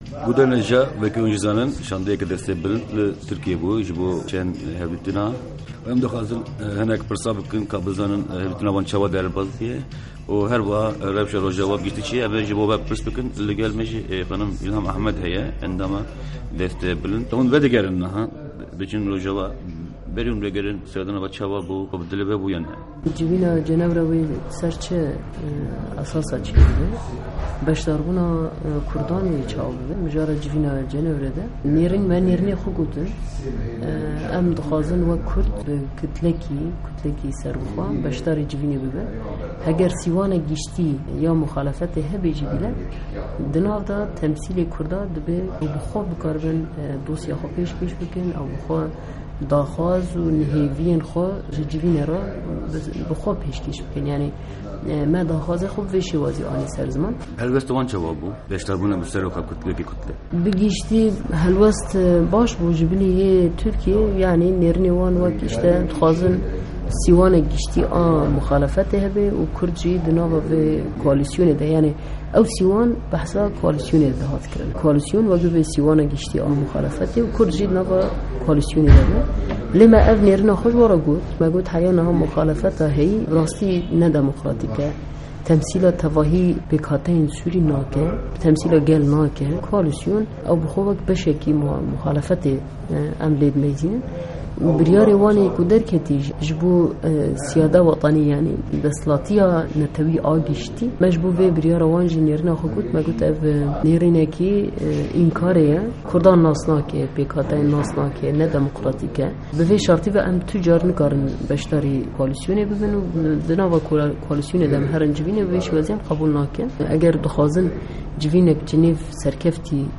Di hevpeyvîna Dengê Amerîka de Îlham Ahmed, endama rêvebirîya Tevgera Civaka Demokratîk (TEV-DEM) û endama Desteya Bilind a Kurd, ser mijarên cihêrengî diaxife.